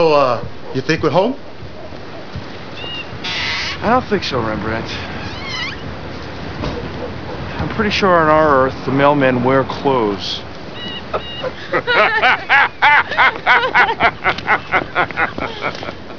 Everyone Laughs....